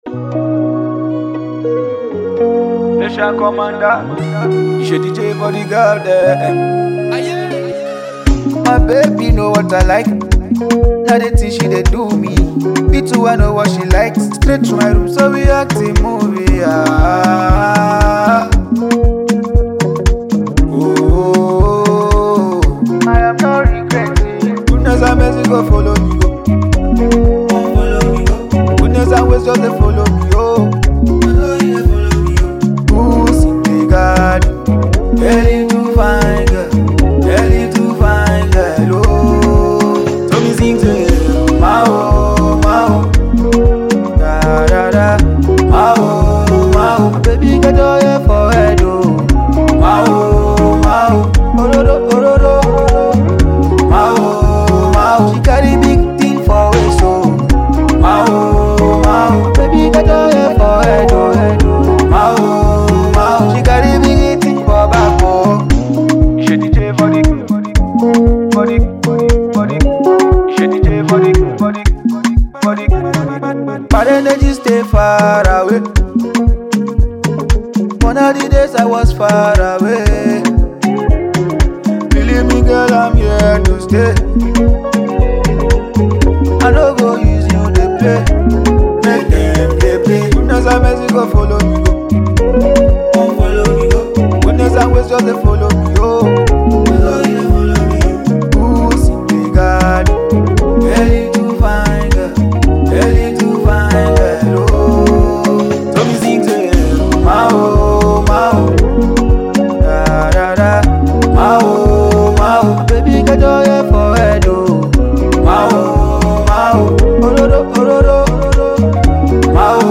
afro highlife fusion
Nigeria’s foremost contemporary guitarist
Makosa spiced Afrolife jam